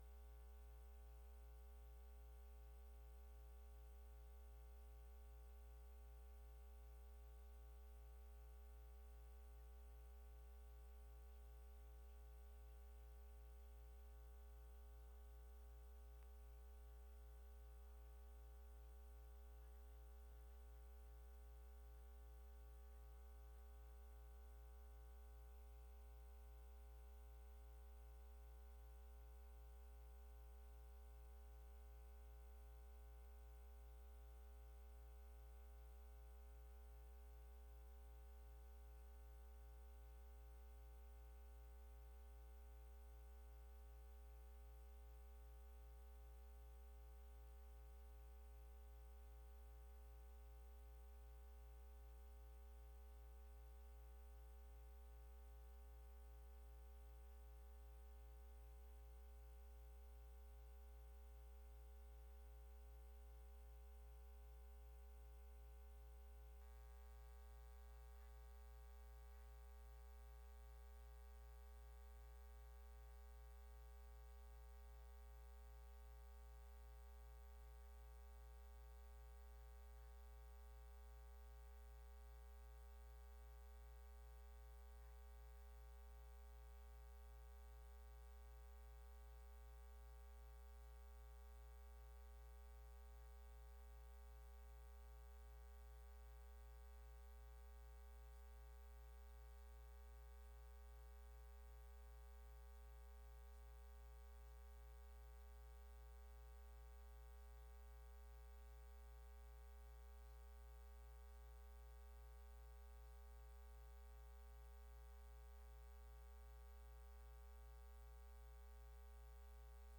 Áudio da Sessão